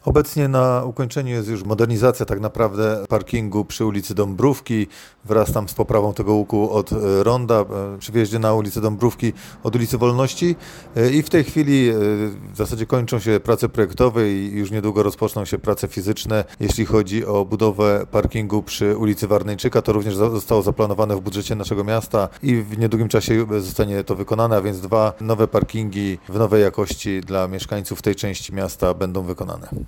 W Mielcu dobiegają również prace związane z powstawaniem kolejnych miejsc do parkowania, których jak się okazuje, jest ciągle za mało. Kolejne z nich czekają na zaprojektowanie, mówi wiceprezydent Mielca Paweł Pazdan.